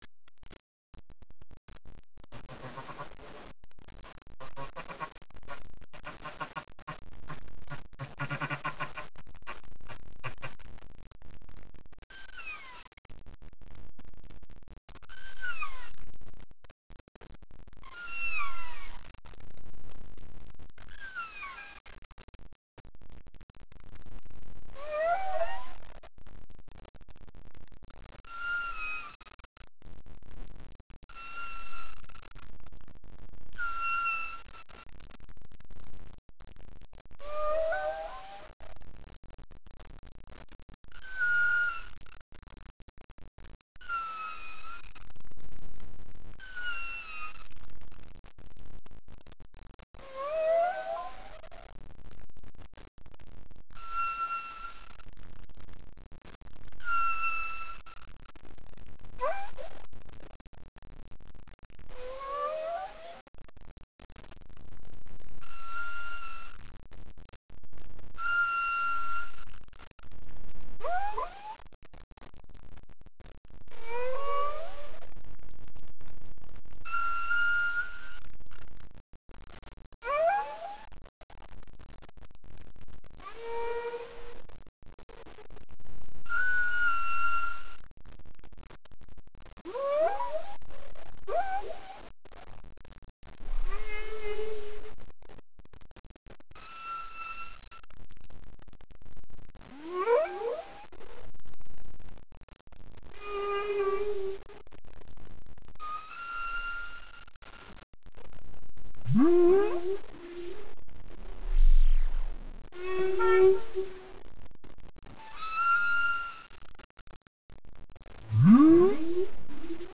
Nom commun : Baleine à bosse
Nom latin : Megaptera novaeangliae
Les sons sous-marins expliqués par Chasseur de sons : parmi eux, celui da la Baleine à bosse !